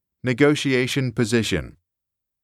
[ni-goh-shee-ey-shuh n] [puh-zish-uh n]